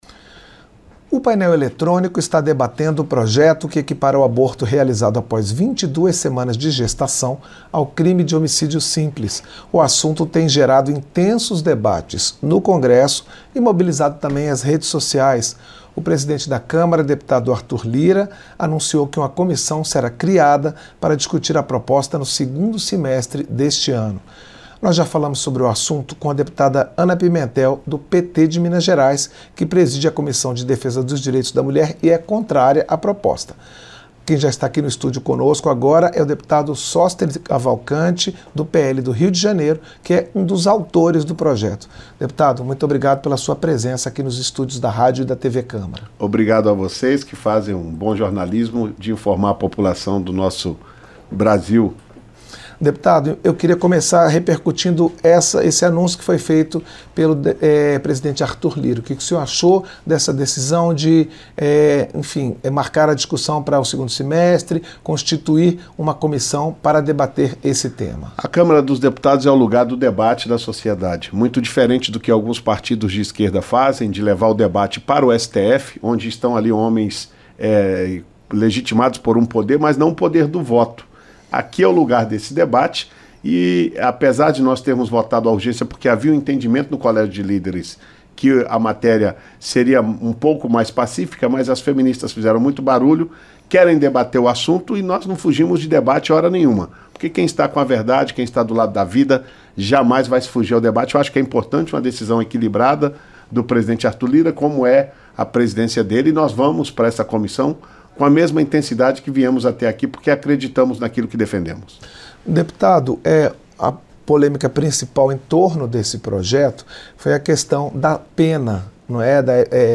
Entrevista - Dep. Sóstenes Cavalcante (PL-RJ)